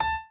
b_pianochord_v100l8o6a.ogg